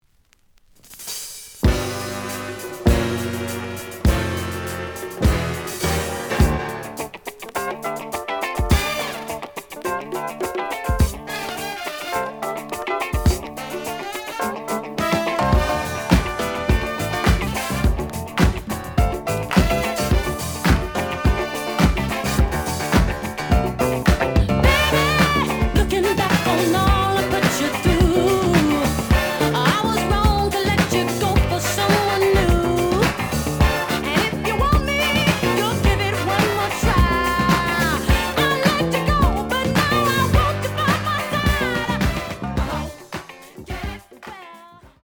The audio sample is recorded from the actual item.
●Genre: Disco
Slight noise on beginning of both side, but almost good.)